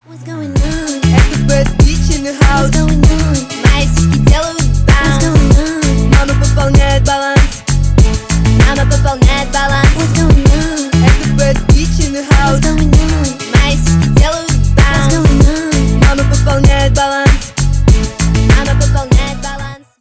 женский рэп